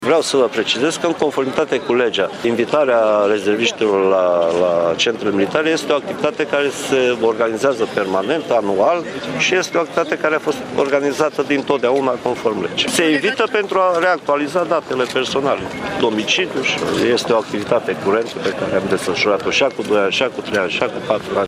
Mircea Dușa a participat astăzi, la Tîrgu-Mureș, la evenimentele care marchează 25 de ani de la înfiinţarea Catedrei de Pregătire Medico-Militară Tîrgu Mureş.